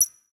{Perc} pick up 3.wav